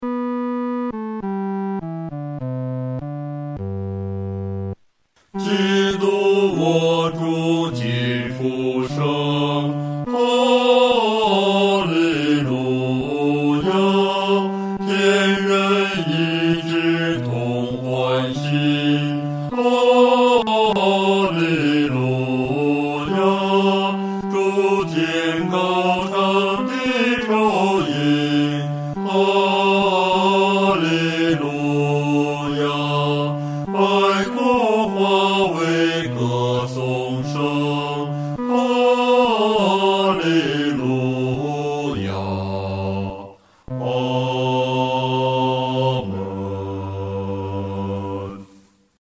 男低
本首圣诗由网上圣诗班录制